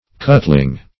Meaning of cutling. cutling synonyms, pronunciation, spelling and more from Free Dictionary.
Search Result for " cutling" : The Collaborative International Dictionary of English v.0.48: Cutling \Cut"ling\ (k[u^]t"l[i^]ng), n., [Cf. Cuttle a knife.] The art of making edged tools or cutlery.